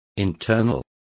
Complete with pronunciation of the translation of internal.